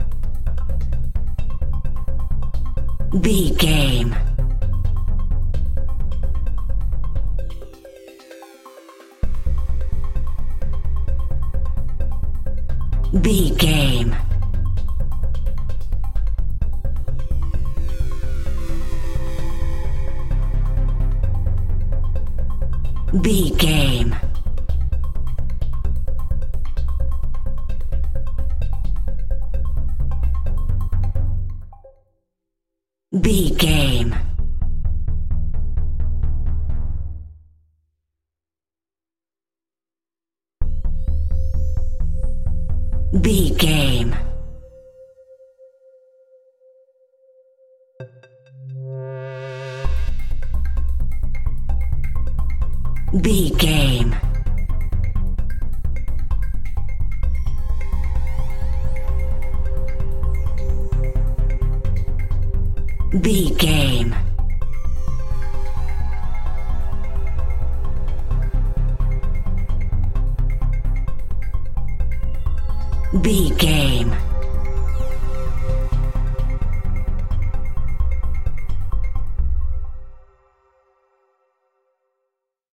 suspenseful
synthesizer
haunting
eerie
hypnotic
medium tempo
ominous
drum machine